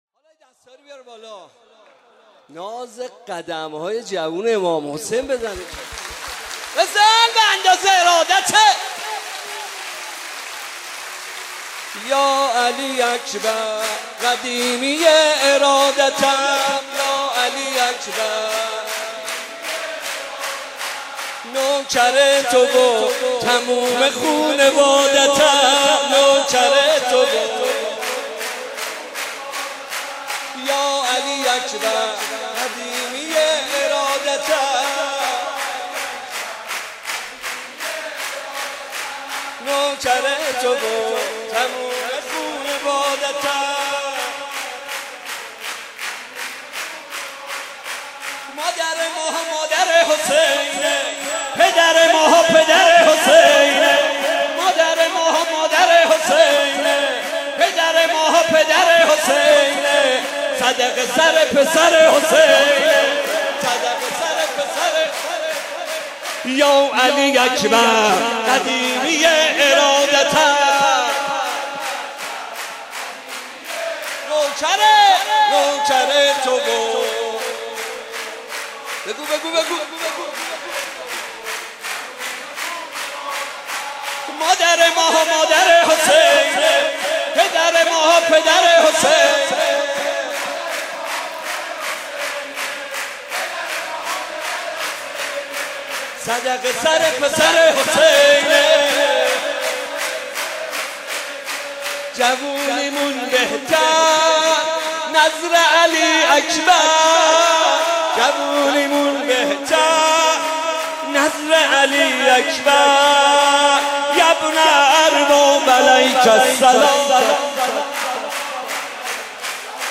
جشن میلاد حضرت علی اکبر(ع) / هیئت مکتب الزهرا(س)
(مدح)